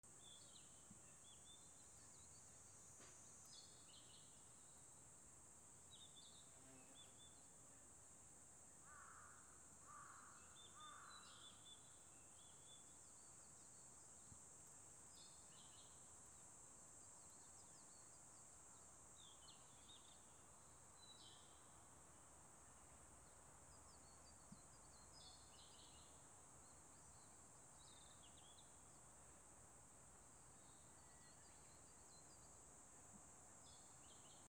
セミの鳴き声 盛岡 資料
/ B｜環境音(自然) / B-25 ｜セミの鳴き声 / セミの鳴き声_70_初夏
盛岡 D50